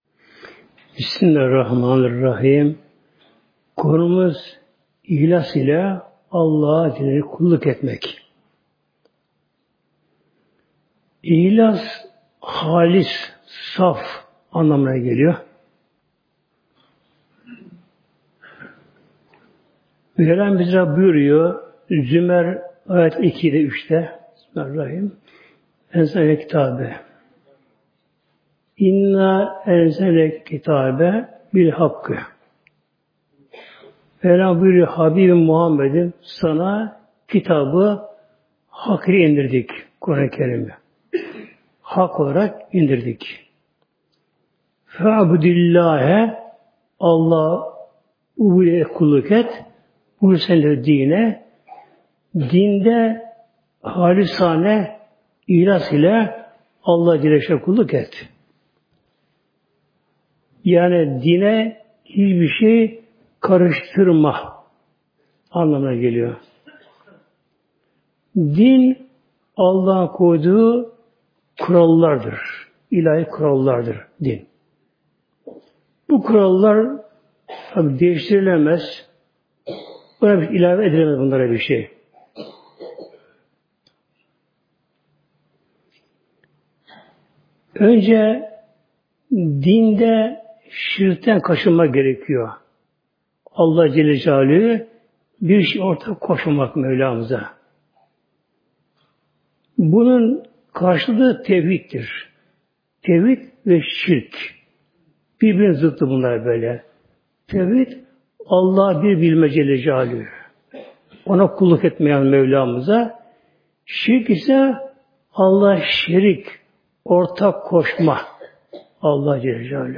Sesli sohbeti indirmek için tıklayın (veya Sağ tıklayıp bağlantıyı farklı kaydet seçiniz)